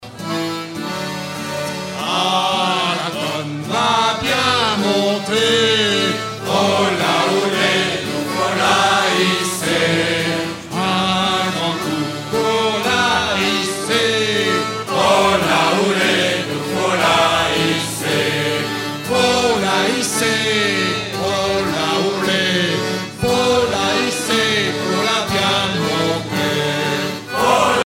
circonstance : maritimes
Genre laisse
Pièce musicale éditée